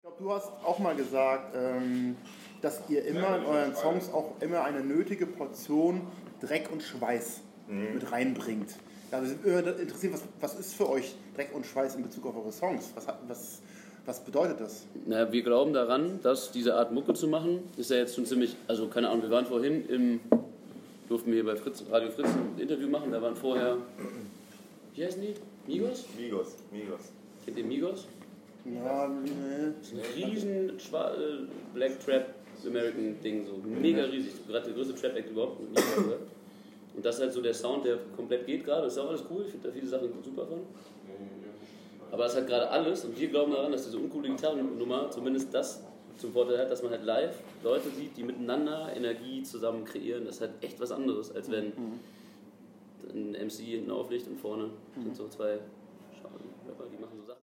Die Jungs lümmeln ein bisschen rum, wie man das so macht im Backstage-Bereich.